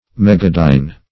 Search Result for " megadyne" : The Collaborative International Dictionary of English v.0.48: Megadyne \Meg"a*dyne\, n. [Mega- + dyne.] (Physics) One of the larger measures of force, amounting to one million dynes.